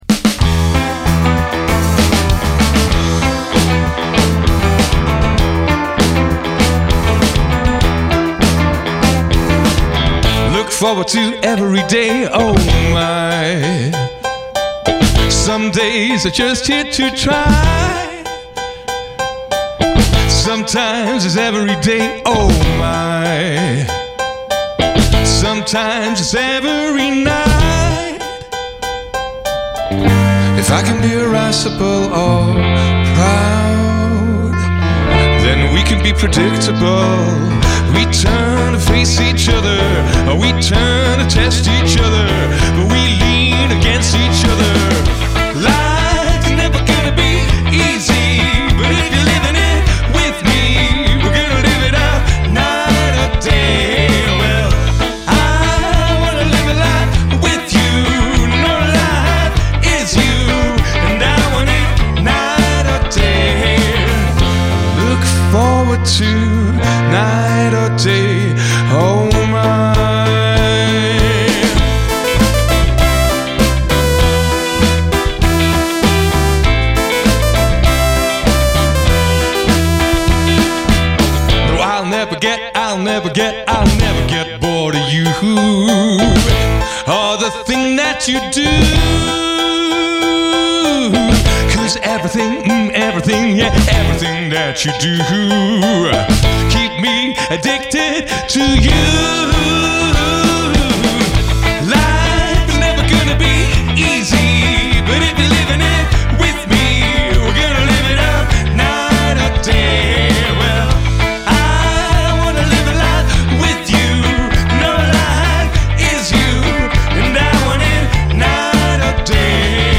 the shift from Post-Punk to Dance worked wonders.<